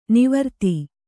♪ nivarti